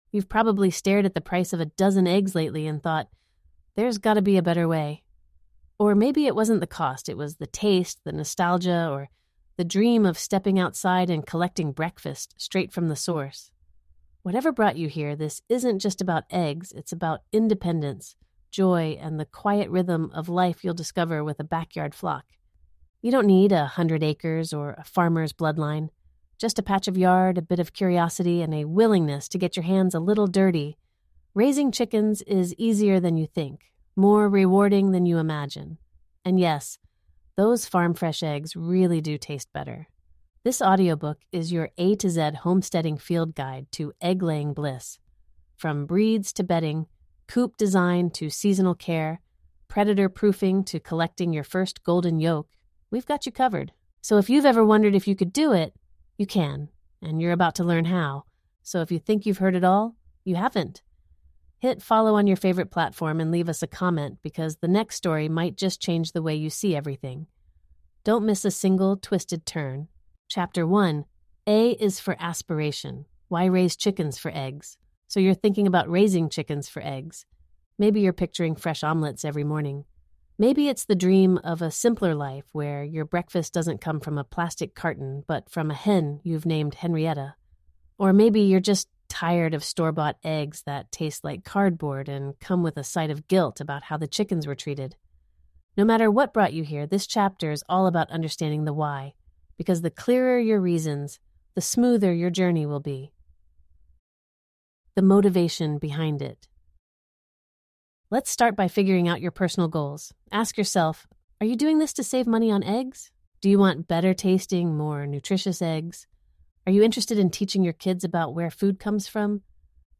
This warm, step-by-step guide takes you from fluffy chicks to seasoned hens, covering every letter of the alphabet with practical wisdom, backyard know-how, and heartfelt lessons from the homesteading life. Whether you're just starting or looking to deepen your self-sufficient journey, this cinematic, second-person audiobook is packed with everything you need to build a flock—and a lifestyle—that lasts.